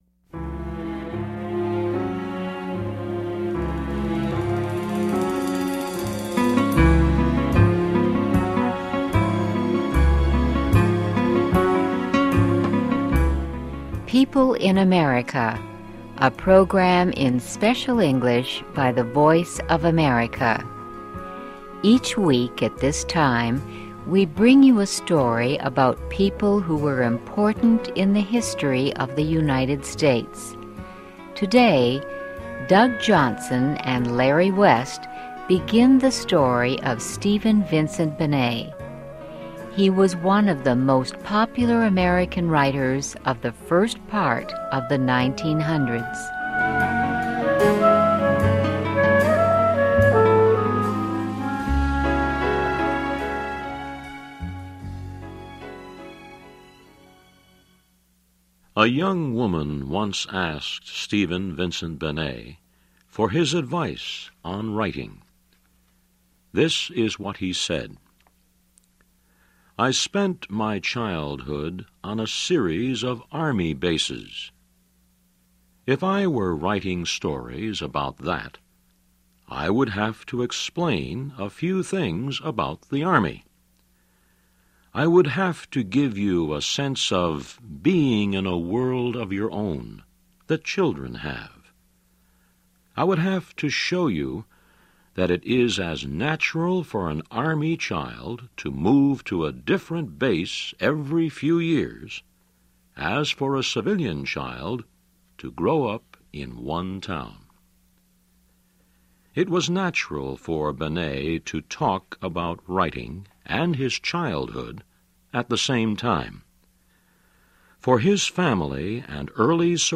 PEOPLE IN AMERICA -- a program in Special English by the Voice of America.